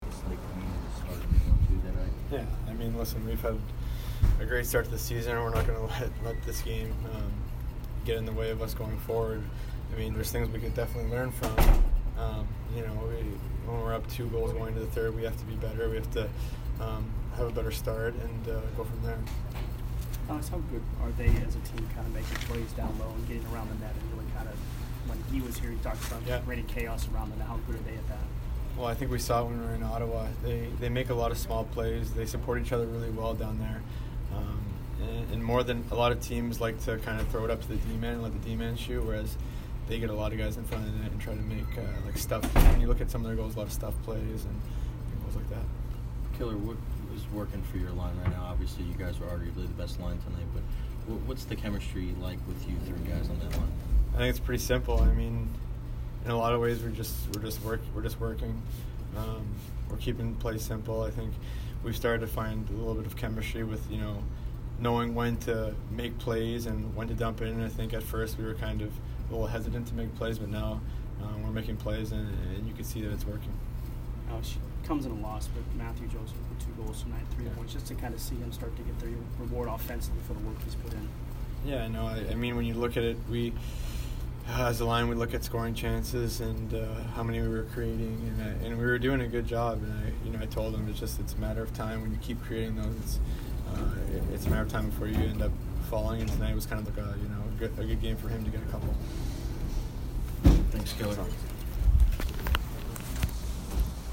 Alex Killorn post-game 11/10